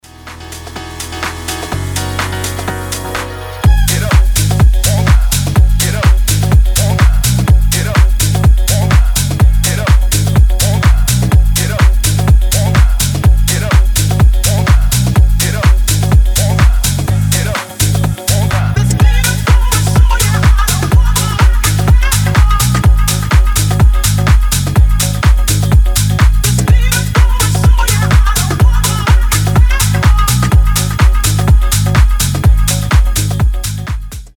танцевальные
piano house
зажигательные